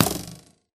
bowhit2.ogg